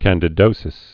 (kăndĭ-dōsĭs)